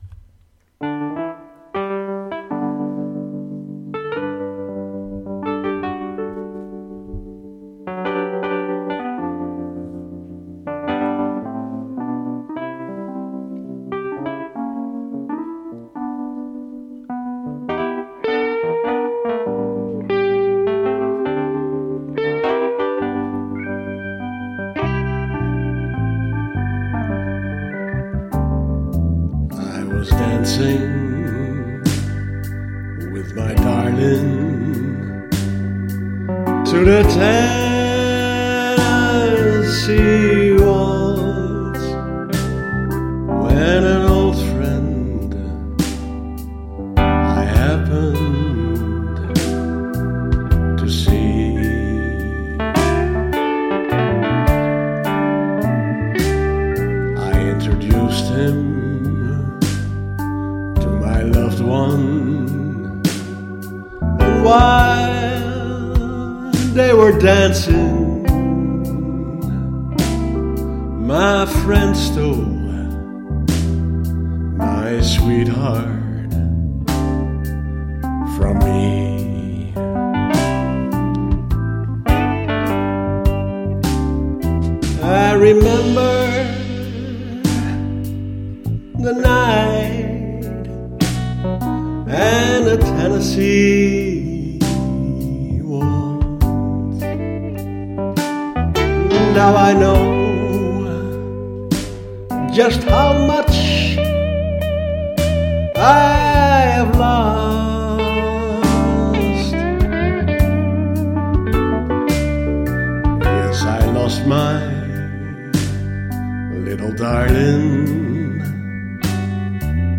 sung by me :-)